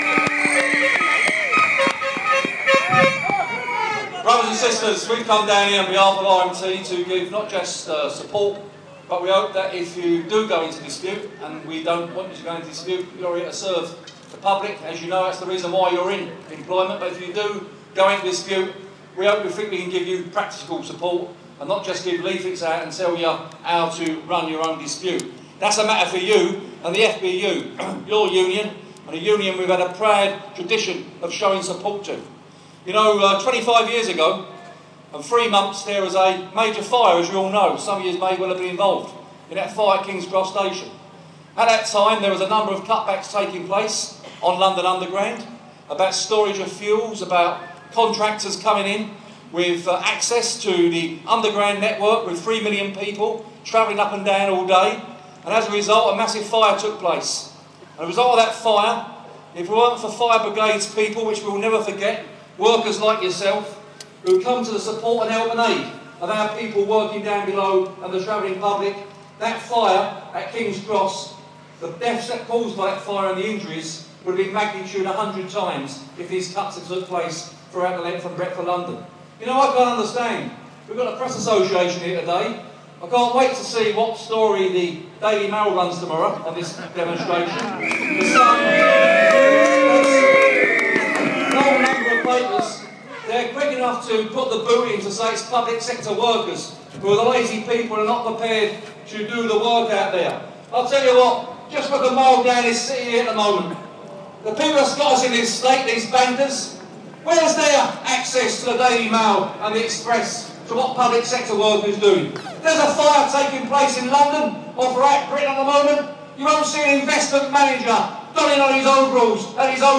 Bob Crow at fire cuts demo